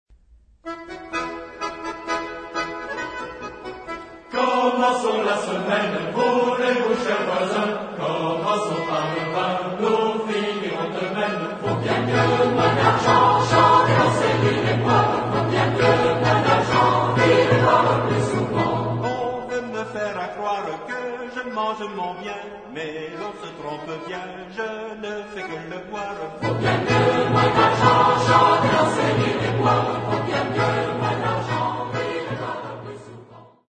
Genre-Style-Forme : Profane ; Chanson à boire ; Populaire
Type de choeur : SATB  (4 voix mixtes )
Solistes : Tenor (1)  (1 soliste(s))
Tonalité : mi mineur ; mi majeur